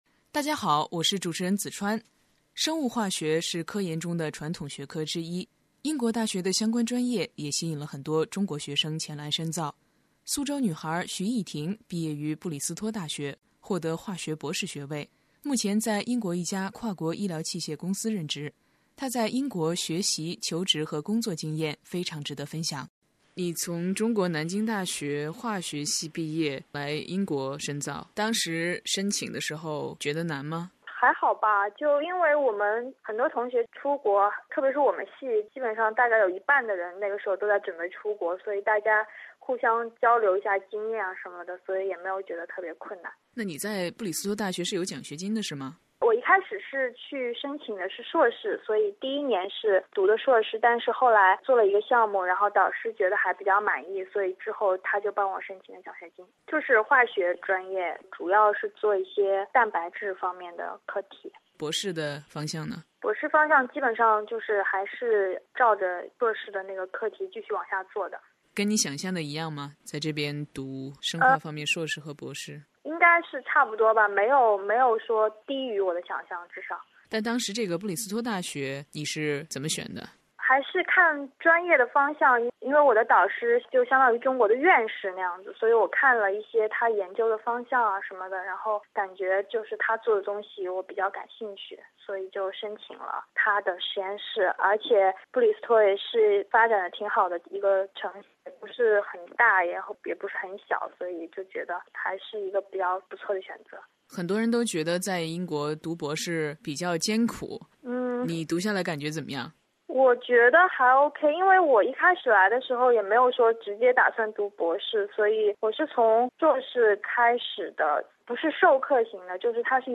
专访学生、教师及教育专家，提供权威、实用的留学信息和解答。